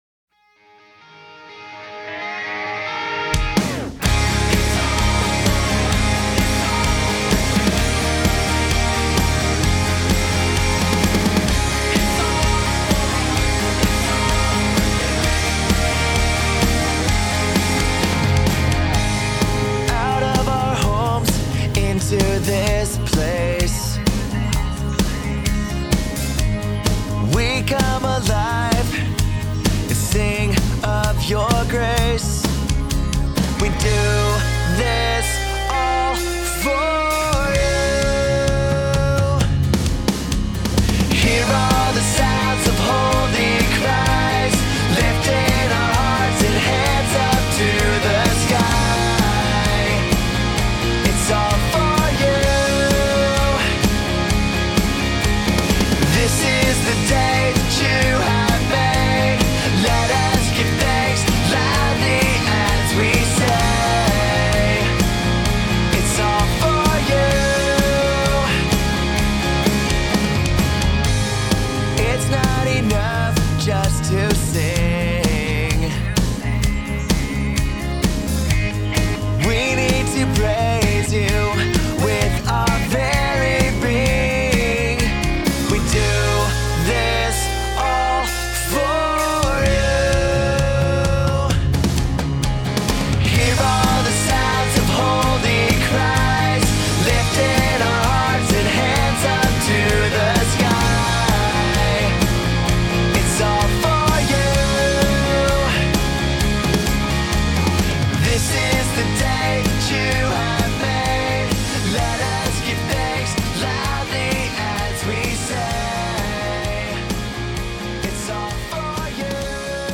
Voicing: 2-part Choir, assembly, cantor